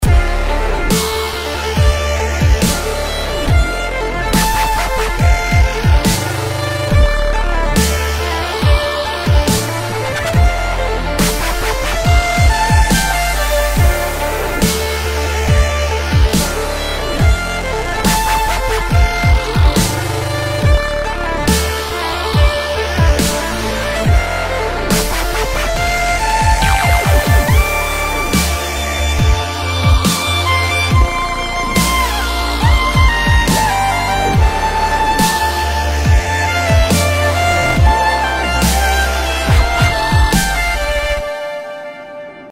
зарубежные.